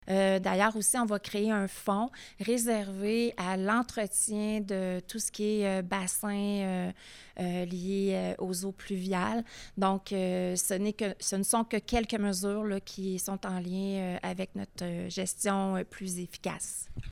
À plusieurs reprises, lors de son énoncé sur le budget 2025, qui était présenté lundi aux médias, la mairesse a évoqué la question des changements climatiques.